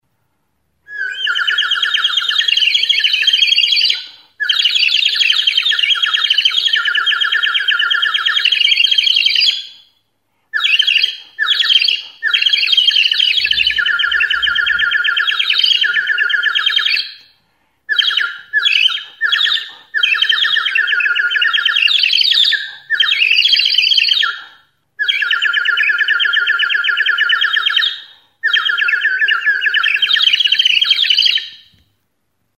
ROUXINOL; Ur flauta | Soinuenea Herri Musikaren Txokoa
Enregistr� avec cet instrument de musique.
Okarina gisako ur flauta da.
Audio Rouxinol-ur flauta joaldia.
Instruments de musique: ROUXINOL; Ur flauta Classification: Aérophones -> Flûtes -> Ocarina Emplacement: Erakusketa biltegia; aerofonoak Explication de l'acquisition: Erosia; Barcelos-eko udal artegintza dendan erosia.